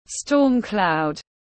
Storm cloud /ˈstɔːm ˌklaʊd/